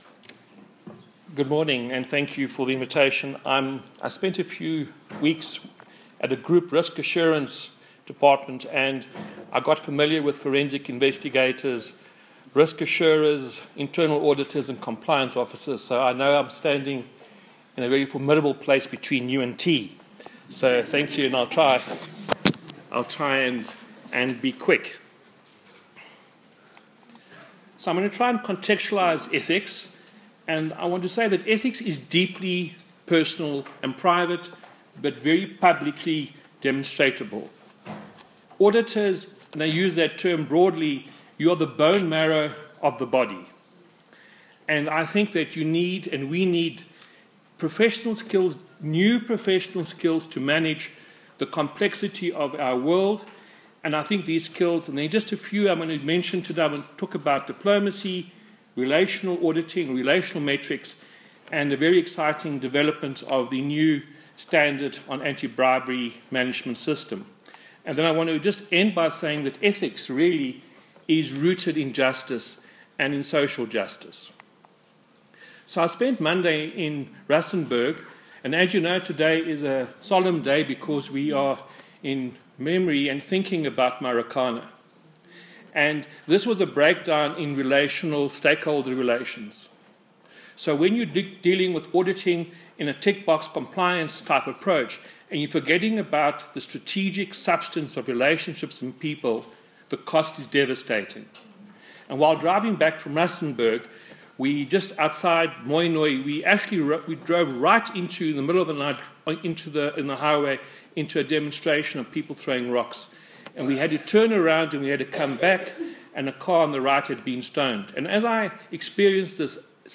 Message given at the South African Auditor & Training Certification Authority (SAATCA) 20th Annual Conference at the CSIR on 16 August 2017. https